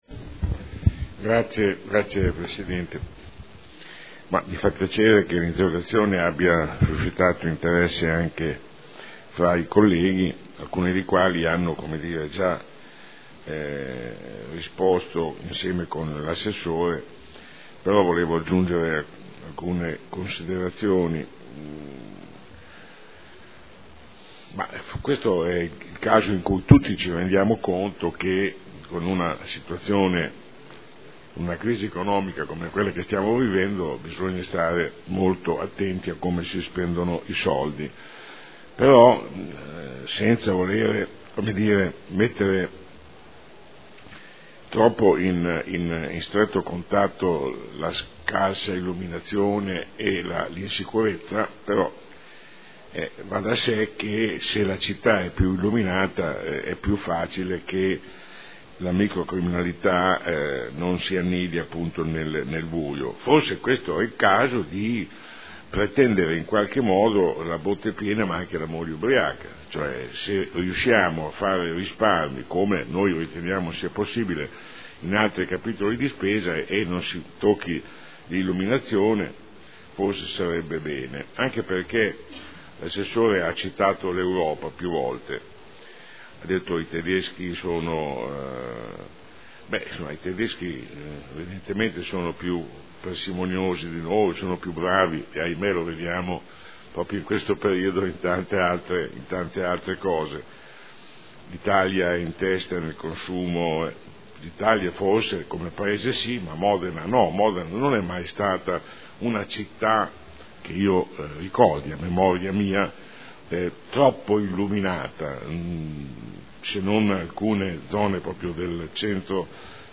Seduta del 17/12/2012. Dibattito su interrogazione del consigliere Bellei (PdL) avente per oggetto: “Modena vuol battere il record di città buia” e interrogazione del consigliere Bellei (PdL) avente per oggetto: “Scarsa illuminazione.